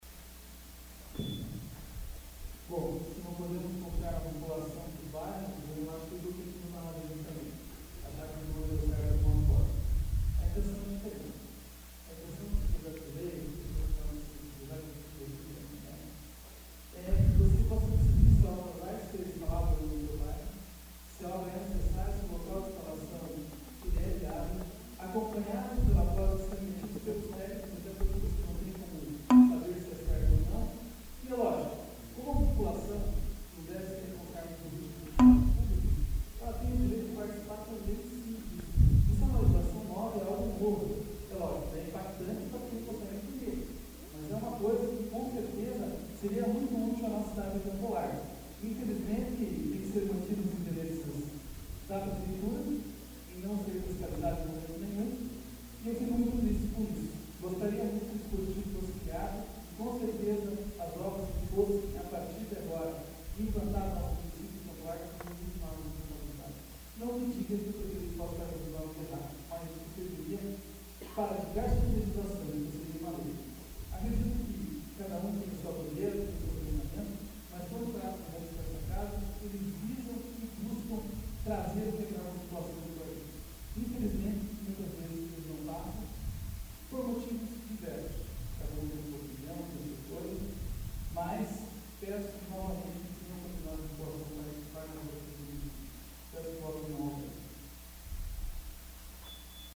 Discussão do veto AVULSO 11/03/2014 João Marcos Cuba